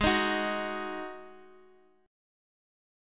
D#mbb5/G# chord